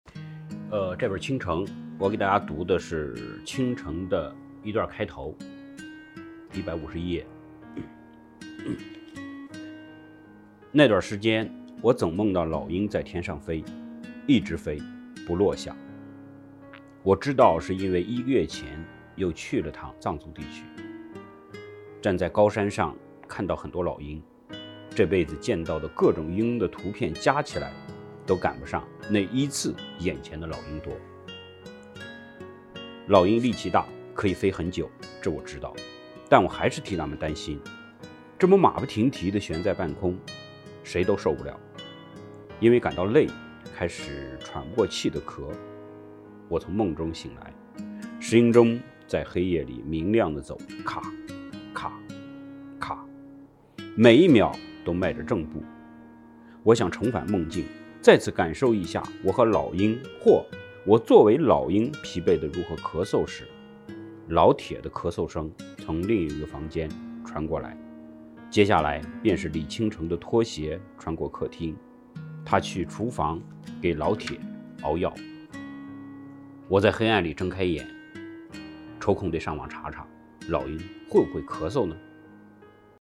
点击下方音频收听徐则臣朗读《如果大雪封门》片段